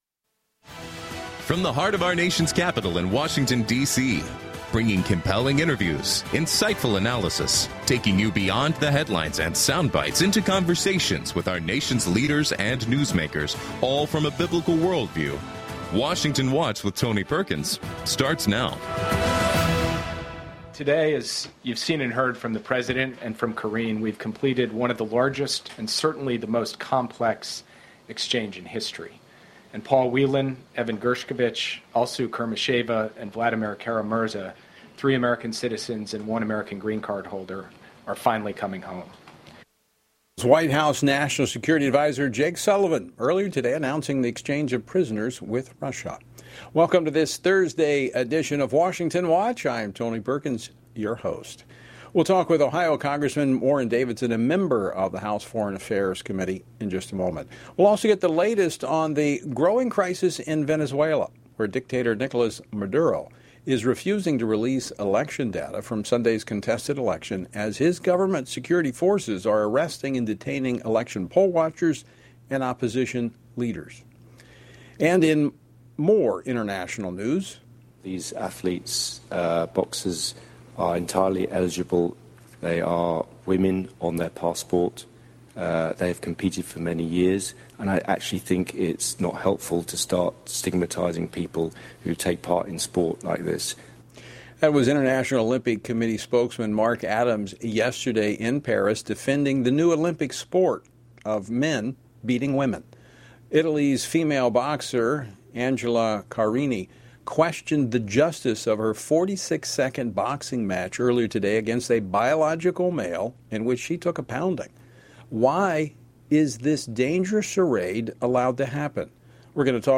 On today’s program: Warren Davidson, U.S. Representative for the 8th District of Ohio, reacts to the massive hostage swap between seven countries and the unrest in Venezuela. Greg Steube, U.S. Representative for the 17th District of Florida, comments on why it’s important to defend women’s-only spaces in light of a biologically male Olympic boxer defeating a biologically female boxer.